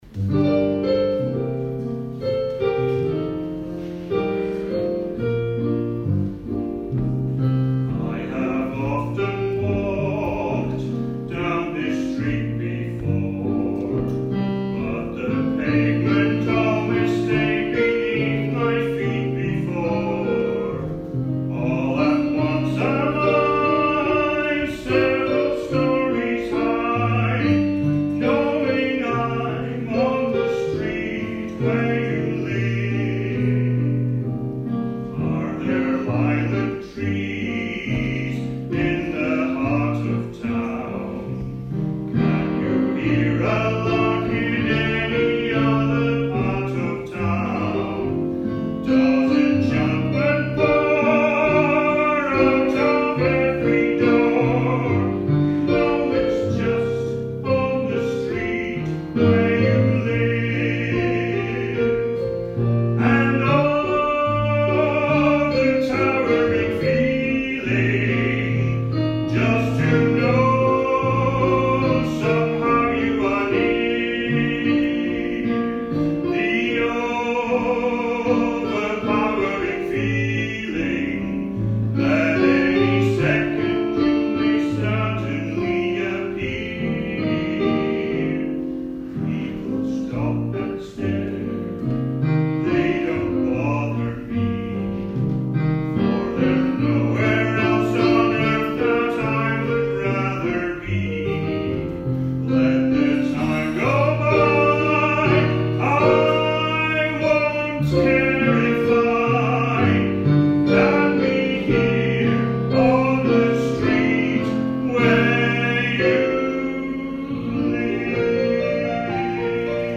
A great crowd turned out for the Guild social coffee evening with the Community Choir entertaining us with  a selection of popular songs.
Music from the shows and favourite films, with some scottish extras brought back memories for many of the audience, with a few singing along to those golden favourites.